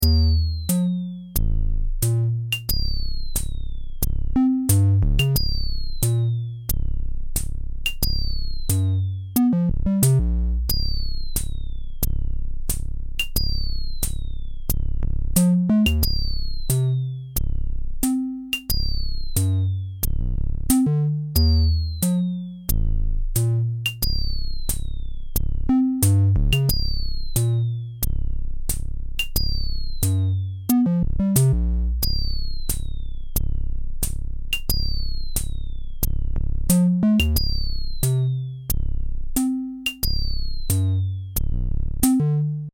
Bucle de percusión electrónica
percusión
melodía
repetitivo
sintetizador